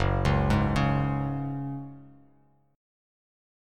F#sus2 chord